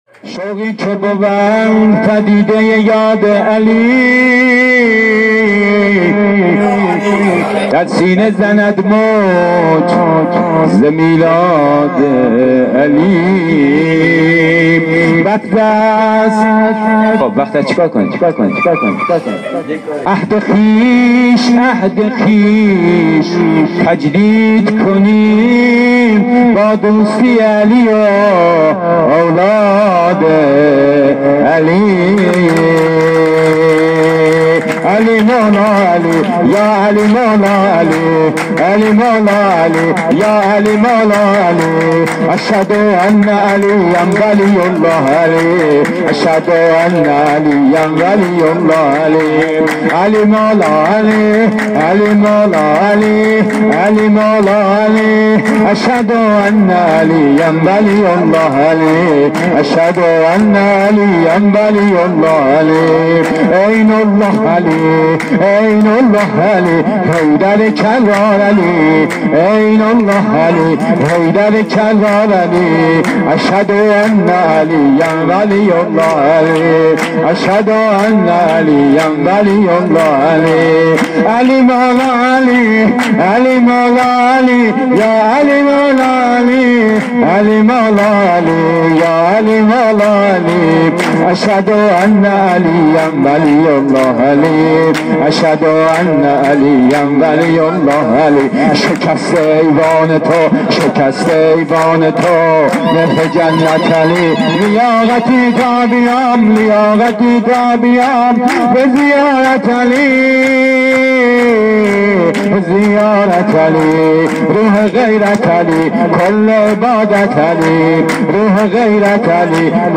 جشن ها
ولادت مولی الموحدین علی (ع) سه شنبه ۲۵ دی ۱۴۰۳